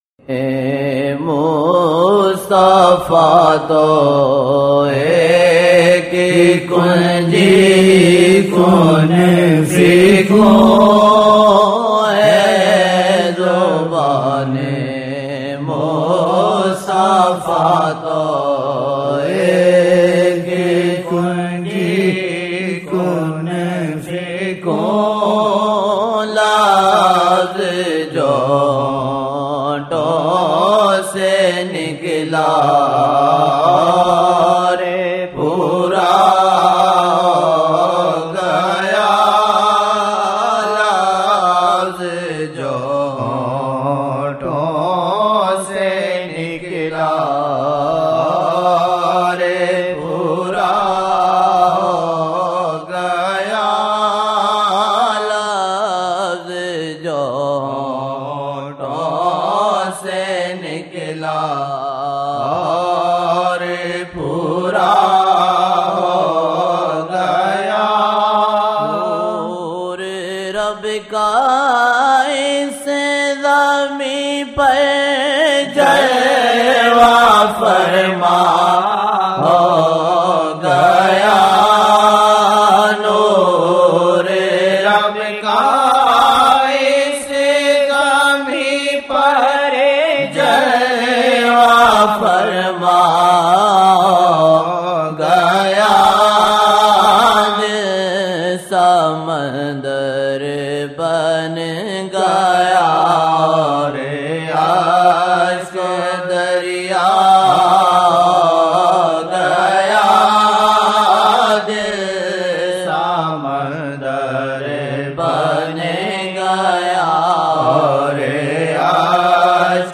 silsila ameenia | » Naat-e-Shareef